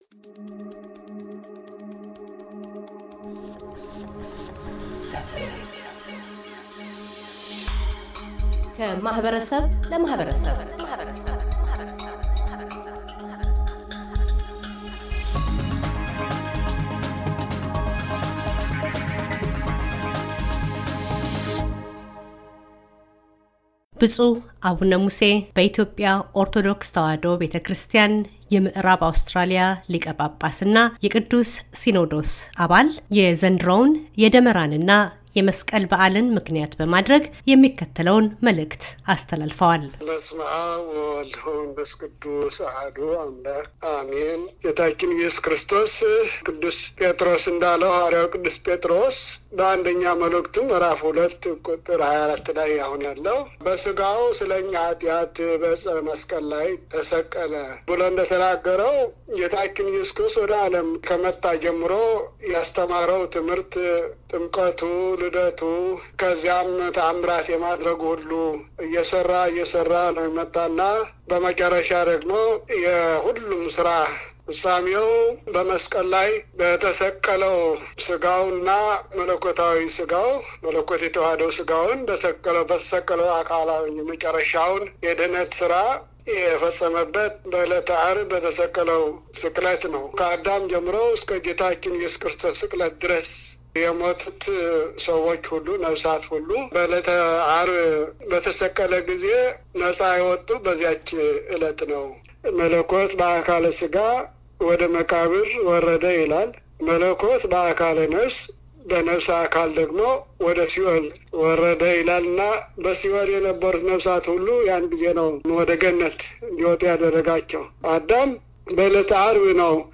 ብፁዕ አቡነ ሙሴ - በኢትዮጵያ ኦርቶዶክስ ተዋሕዶ ቤተክርስቲያን የምዕራብ አውስትራሊያ ሊቀ ጳጳስና የቅዱስ ሲኖዶስ አባል፤ የመስቀል በዓል መልዕክታቸውን ያስተላልፋሉ።